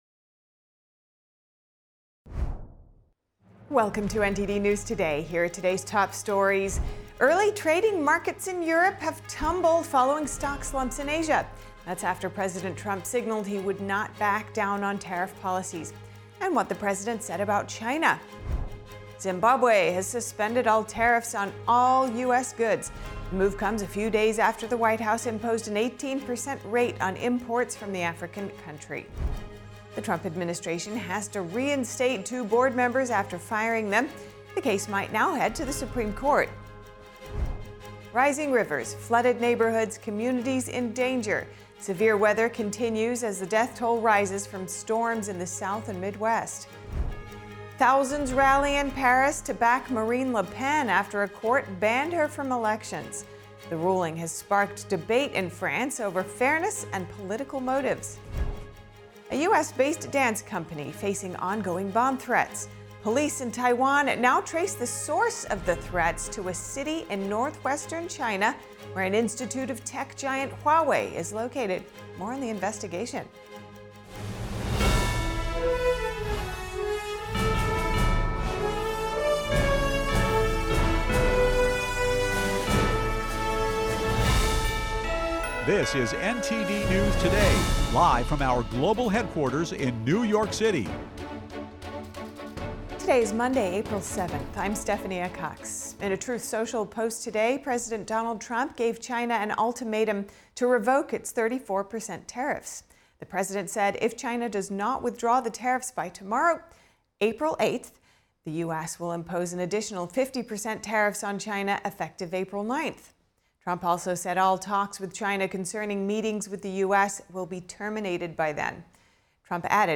NTD-News-Today-Full-Broadcast-April-7-audio-converted.mp3